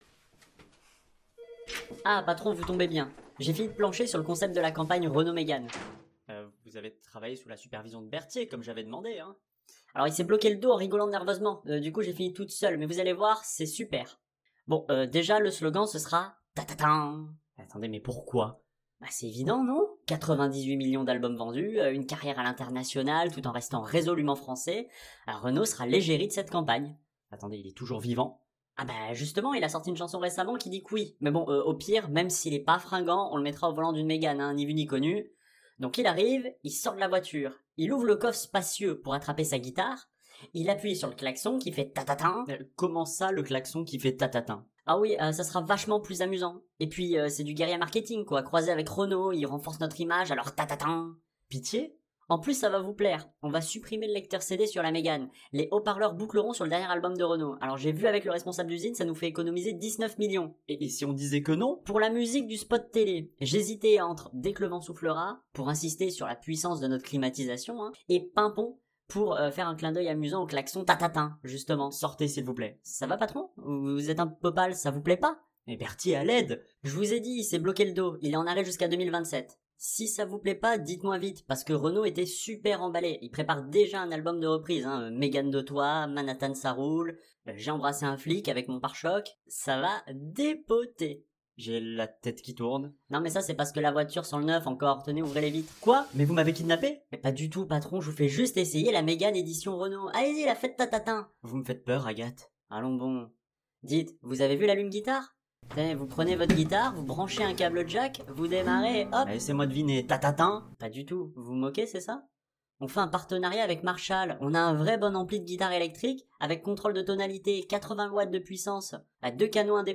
Les voix sont faites façon "2 minutes du peuple"